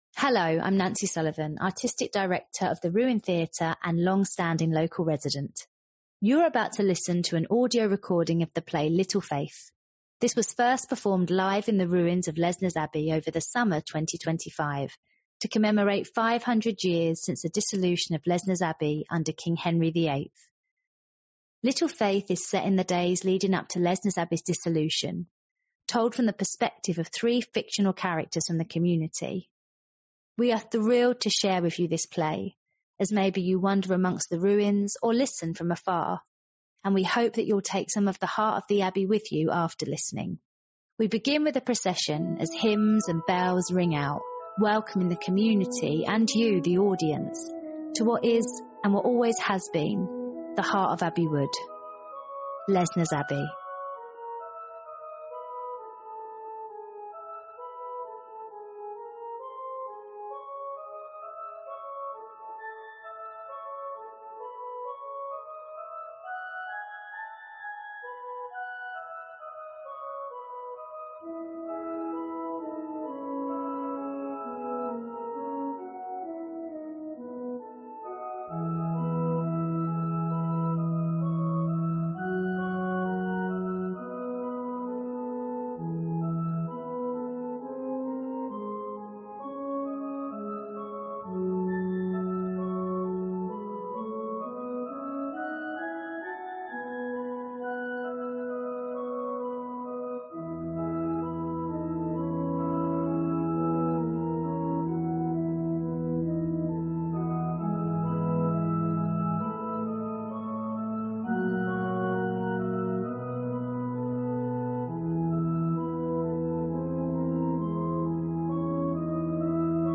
Little Faith - an audio storytelling about Lesnes Abbey